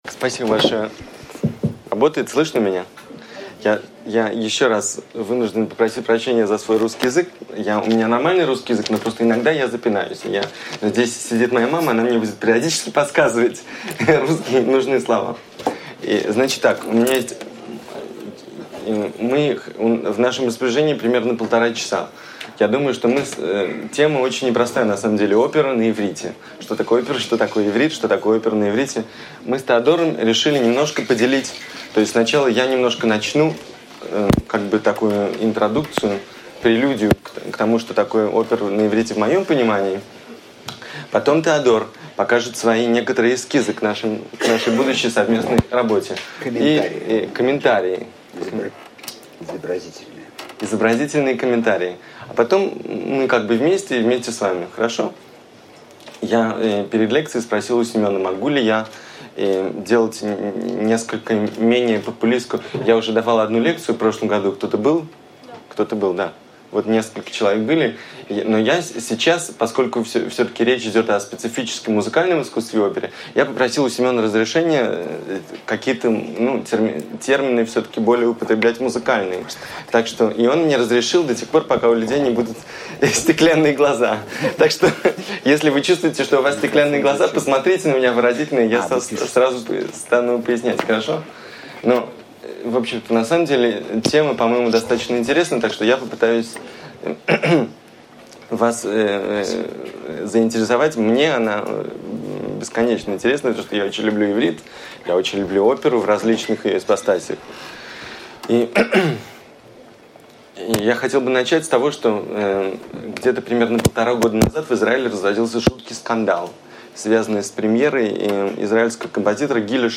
Аудиокнига Опера на иврите | Библиотека аудиокниг